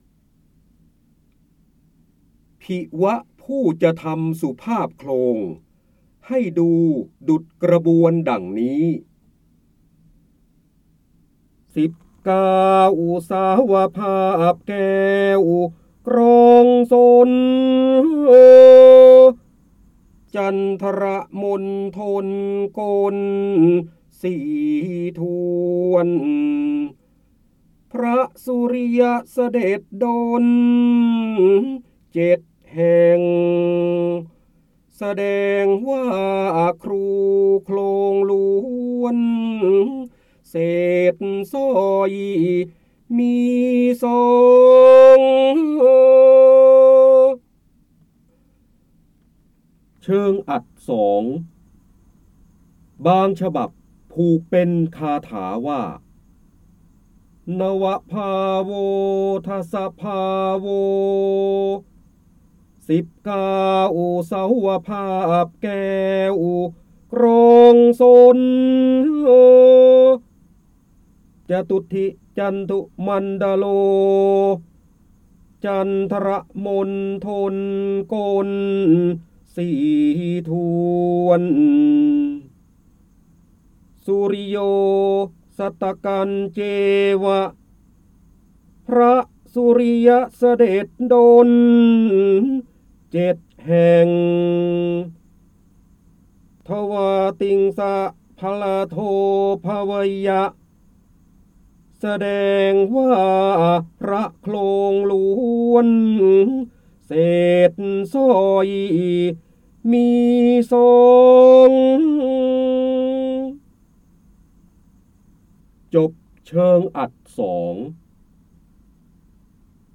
เสียงบรรยายจากหนังสือ จินดามณี (พระโหราธิบดี) ผิว ผู้จะทำสุภาพโคลง ให้ดูดุจกระบวนดั่งนี้
คำสำคัญ : พระเจ้าบรมโกศ, ร้อยแก้ว, จินดามณี, ร้อยกรอง, พระโหราธิบดี, การอ่านออกเสียง